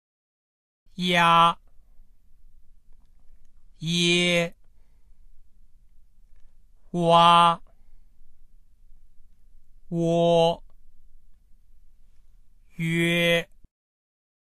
ya[-ia]　　 ye[-ie]　　 wa[-ua]　　 wo[-uo]　　 yue[-e]
ya-ye-wa-wo-yue.mp3